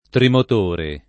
[ trimot 1 re ]